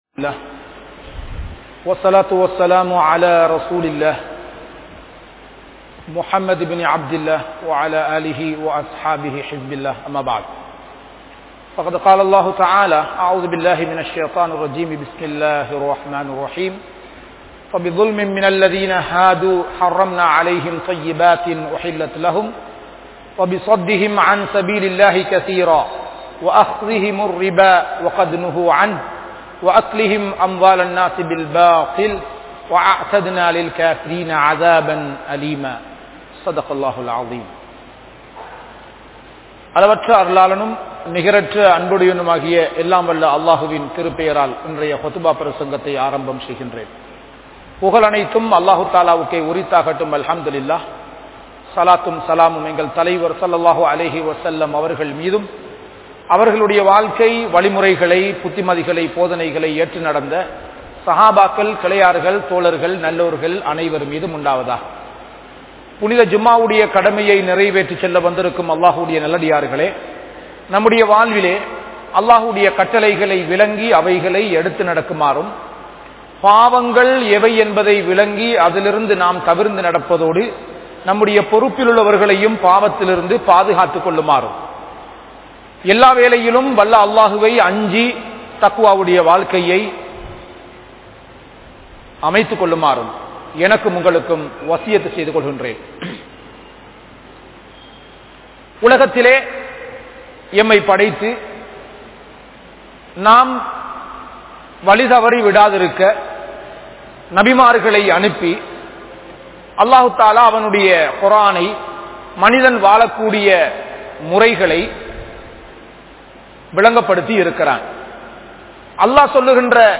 Thaayudan Vifachchaaram Seivathatku Samanaana Paavam (தாயுடன் விபச்சாரம் செய்வதற்கு சமனான பாவம்) | Audio Bayans | All Ceylon Muslim Youth Community | Addalaichenai